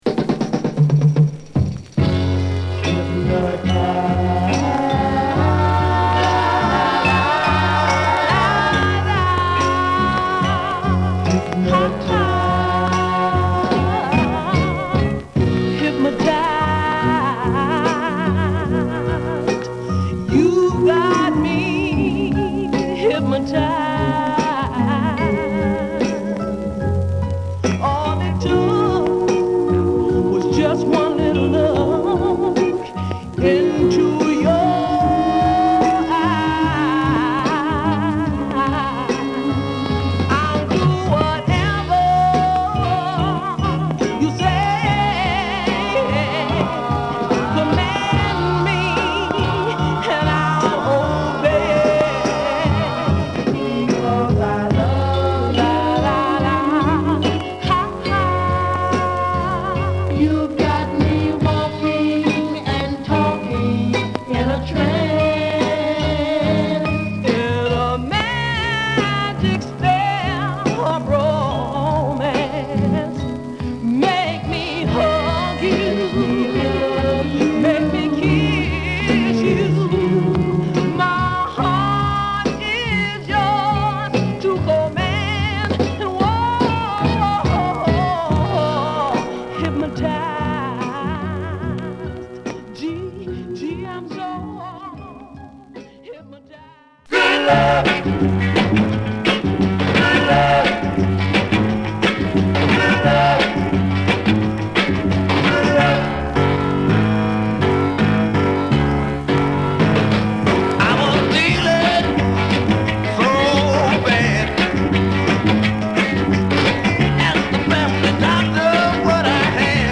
ドゥーワップグループ